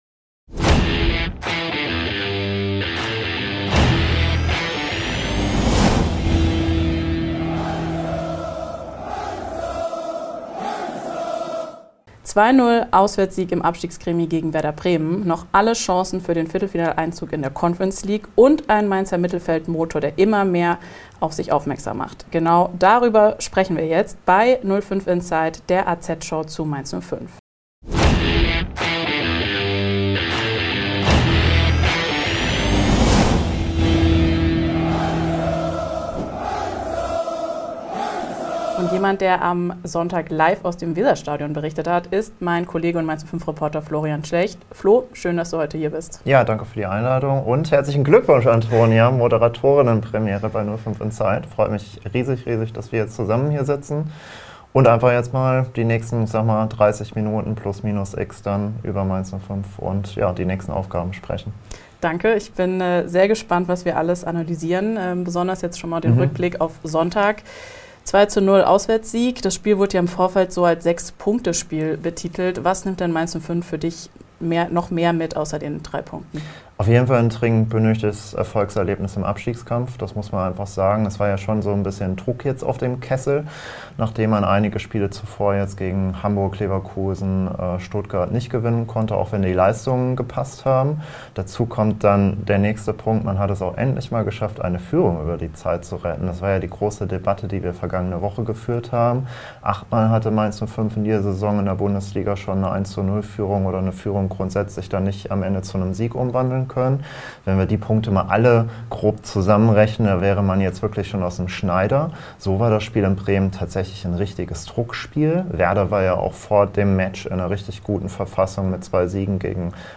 In der neuen Folge „05 Inside“ diskutieren die Mainz-05-Reporter